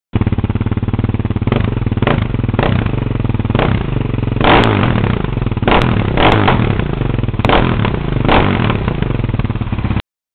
Klicka för att höra Husaberg FE650. Modifierat (öppnare) avgassytem
Husaberg650.mp3